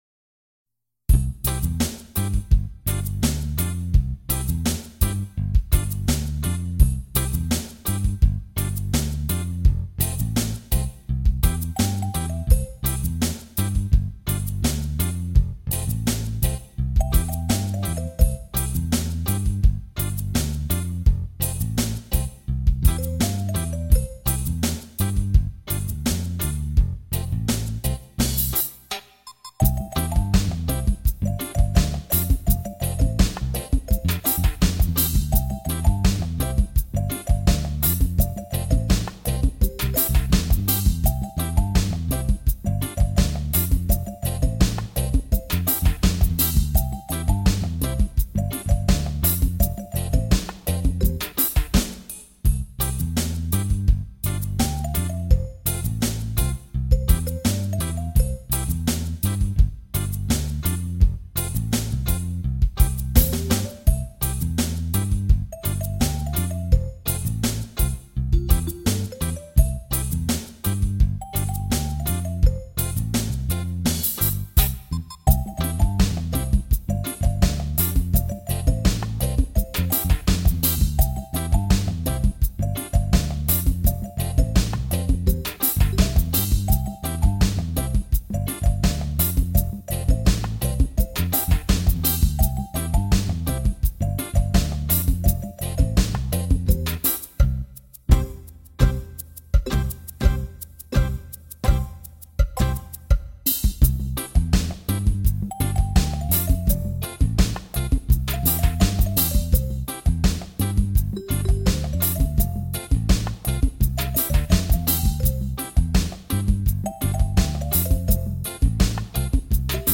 set to a reggae beat, with no vocals
... It would be nice if the vocals were ever added ...